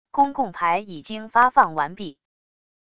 公共牌已经发放完毕wav音频生成系统WAV Audio Player